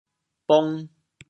潮州 pah4 bong2 siu5 潮阳 pah4 bong2 siu5 潮州 0 1 2 潮阳 0 1 2